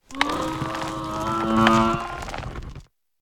Cri de Craparoi dans Pokémon Écarlate et Violet.